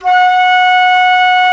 FluteClean_F#3.wav